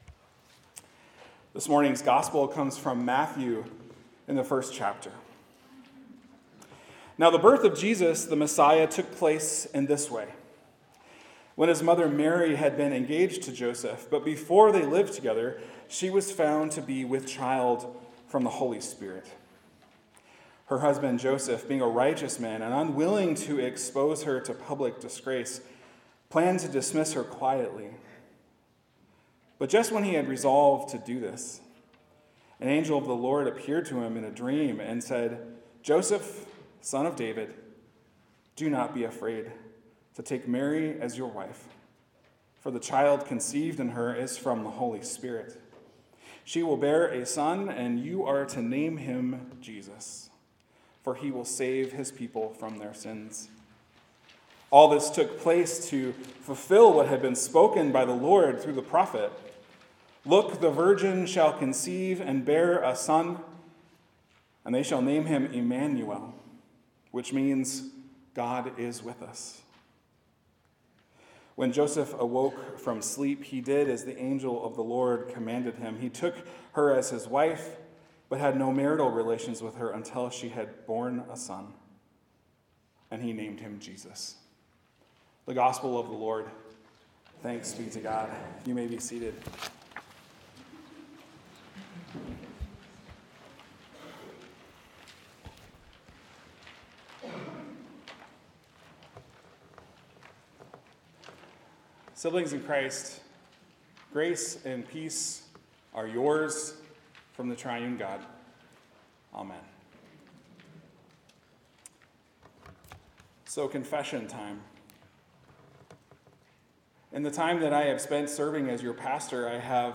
Sermons | Joy Lutheran Church